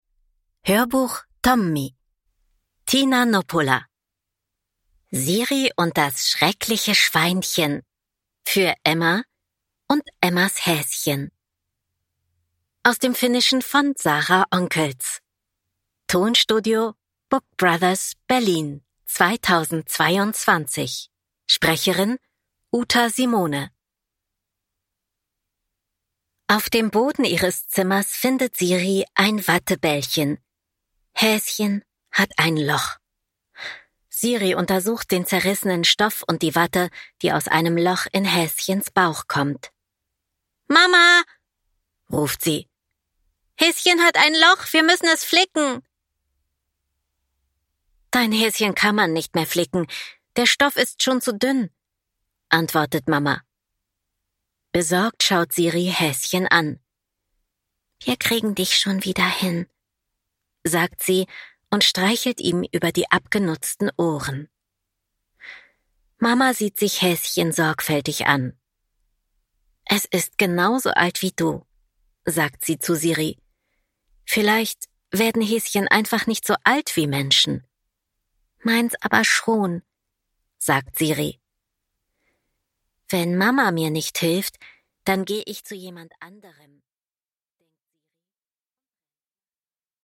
Siri und das schreckliche Schweinchen – Ljudbok – Laddas ner
Einfache Sätze und viel Dialog machen Siris Erlebnisse zu einem großen Hör-Spaß auch für die Kleinen.